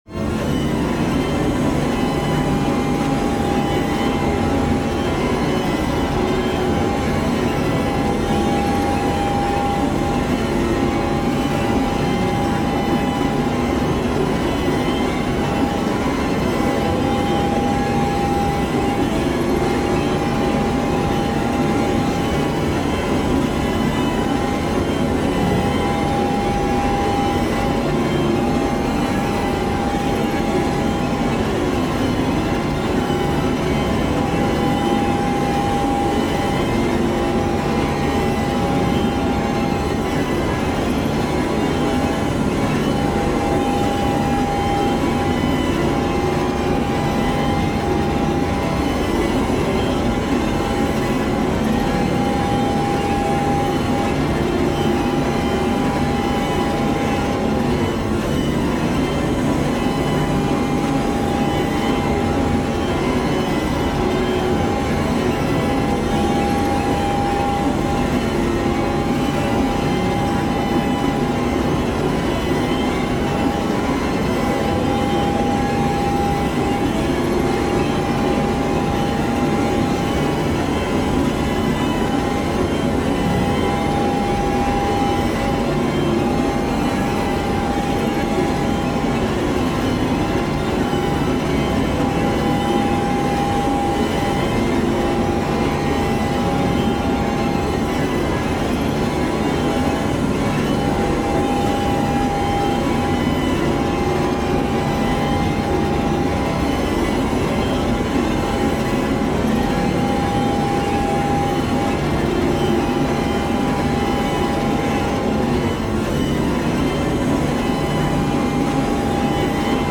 5段階のホラーテクスチャ音源の第四段階。 焦りと恐怖心で平常ではいられなくなり幻聴のようなものも聞こえ始める。
タグ: ホラー/怖い 変わり種 コメント: 5段階のホラーテクスチャ音源の第四段階。